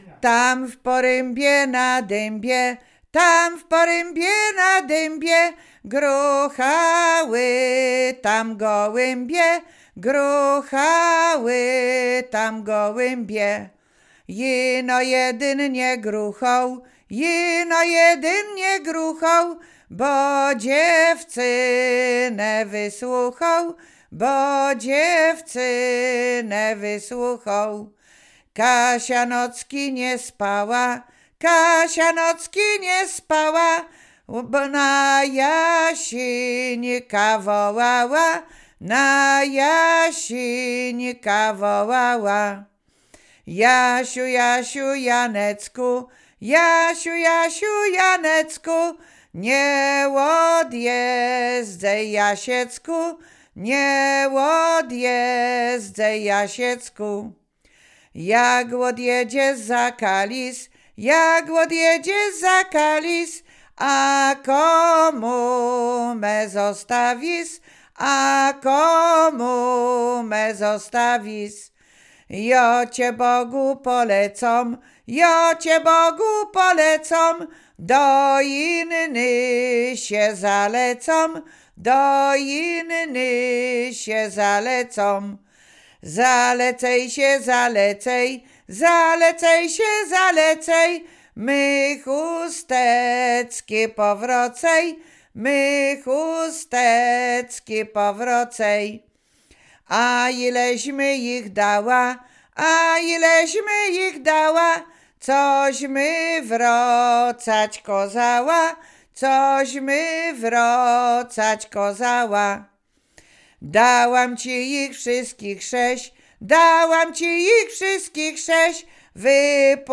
Łowickie
miłosne liryczne